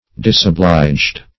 Disoblige \Dis`o*blige"\, v. t. [imp. & p. p. Disobliged; p.